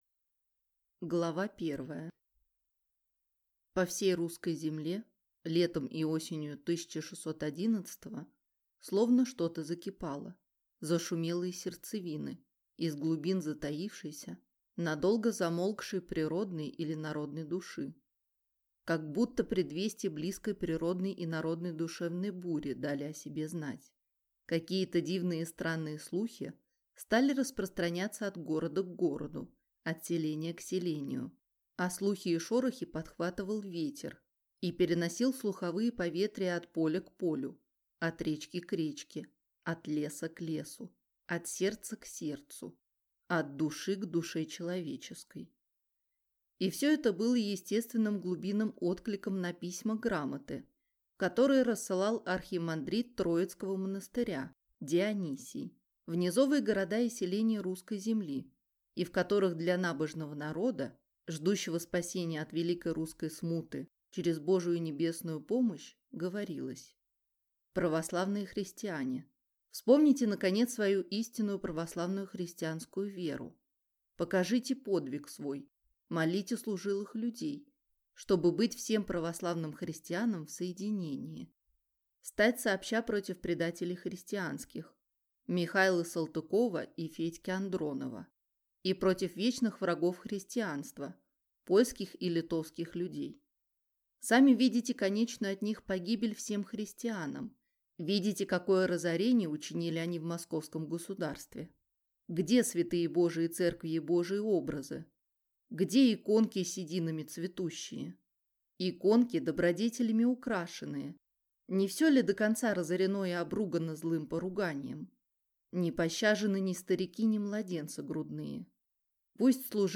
Аудиокнига Годунов. Последняя кровь | Библиотека аудиокниг